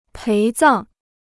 陪葬 (péi zàng) Dictionnaire chinois gratuit